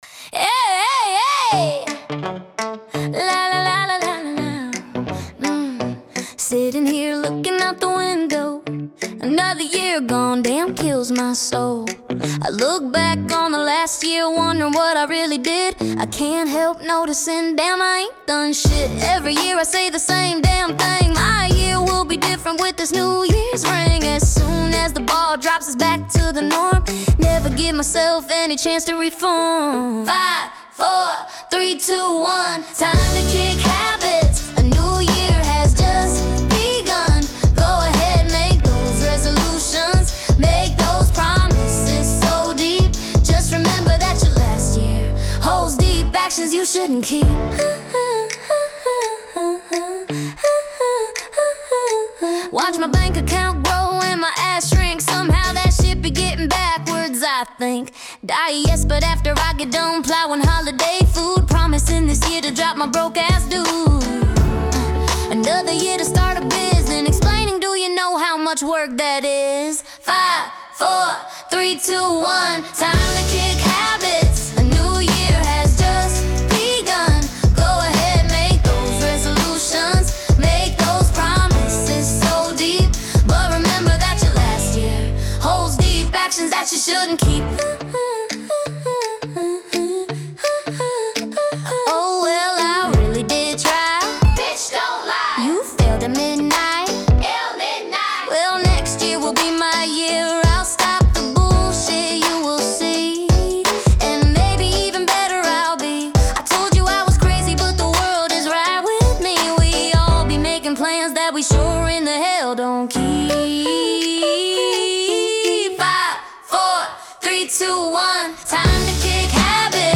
Categories: Pop , Rap